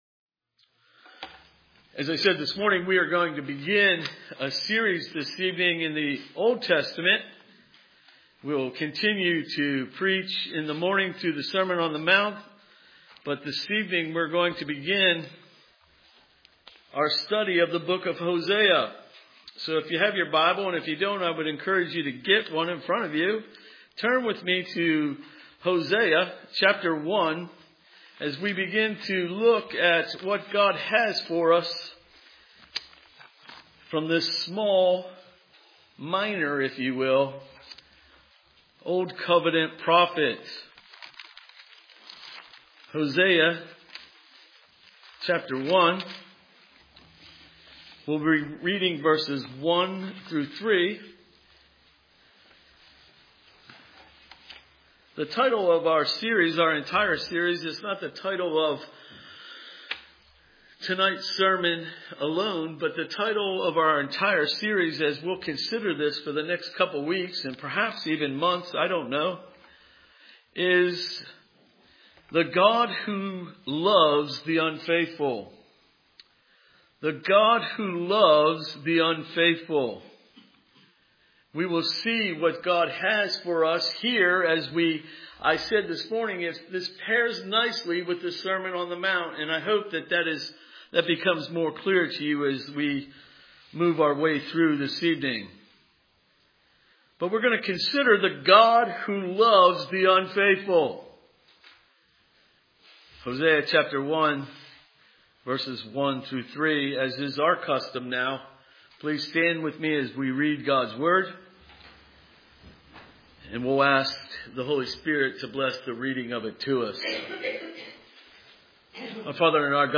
Hosea 1:1-3 Service Type: Sunday Evening Hosea 1:1-3 Hosea gives a covenant love picture.